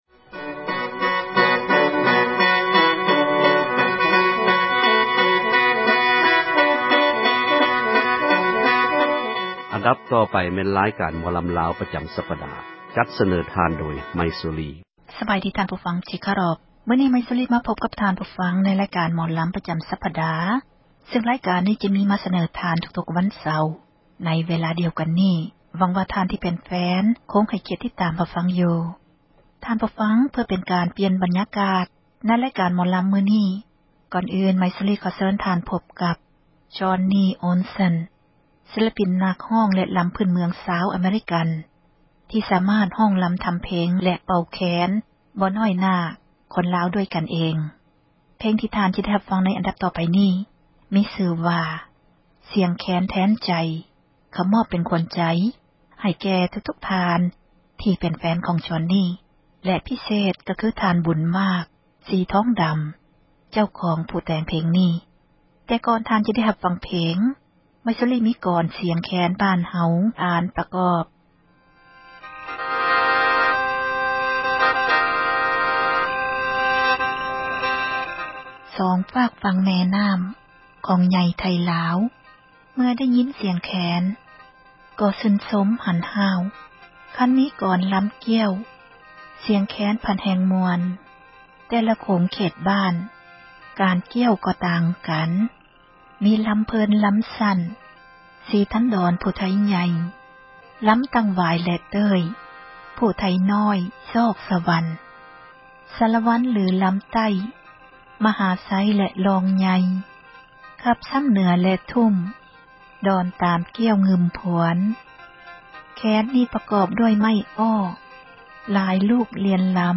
ໃນຣາຍການ ໝໍລຳ ປະຈຳສັປດານີ້ ເຊີນທ່ານຟັງສຽງແຄນ
ລຳ ມະຫາໄຊ